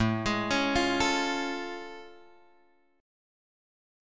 A Augmented Major 7th